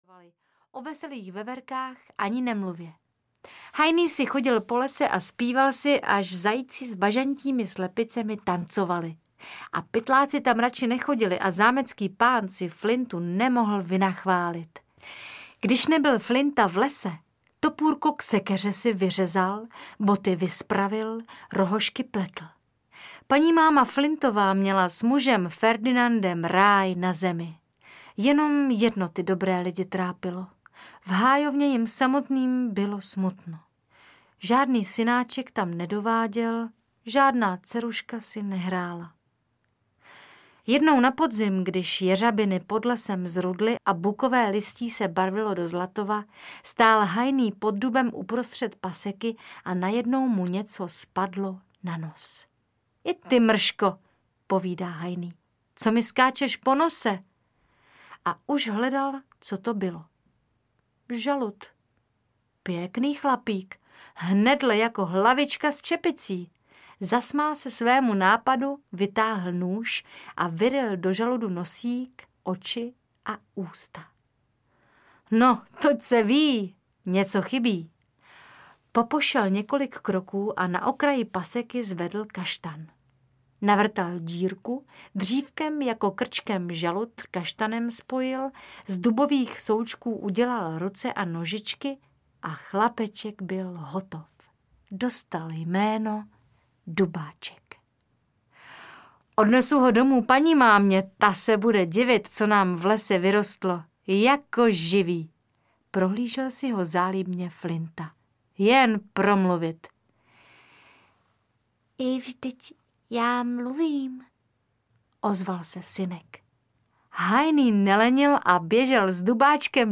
Audiokniha Vypráví pohádky Arturovi, kterou načetla populární zpěvačka Iveta Bartošová.
Ukázka z knihy
Populární zpěvačka tentokrát v nahrávacím studiu nenatáčela písničky, ale vyprávěla pohádky!